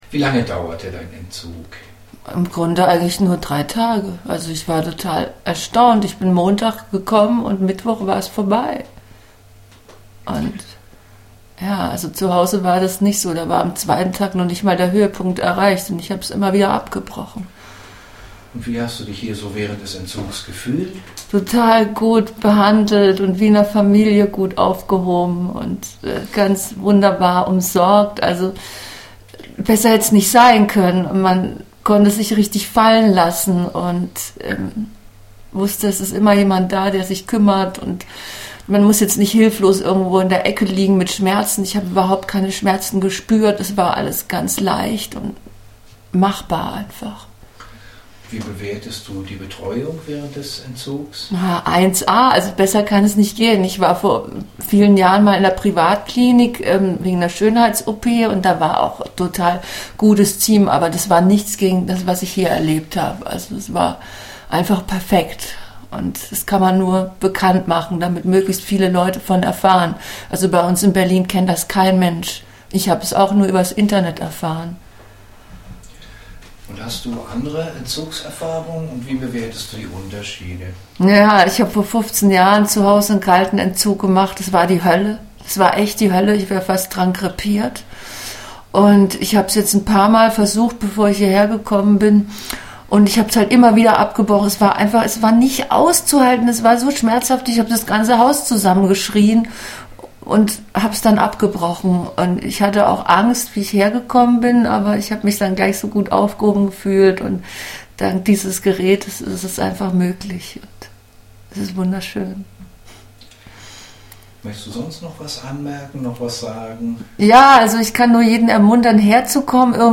Das sagen Patienten aus Deutschland über das ESCAPE-Verfahren